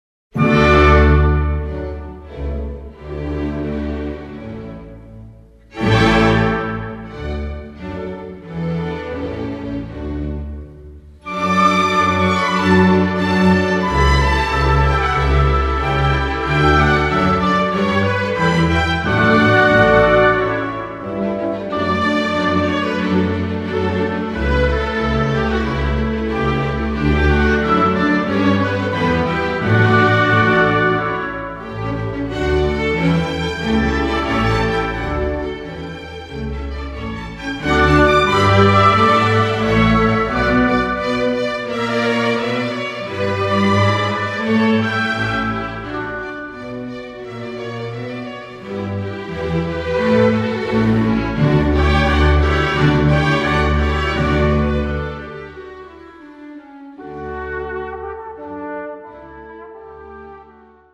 Voicing: Horn, Alto Trombone and Orchestra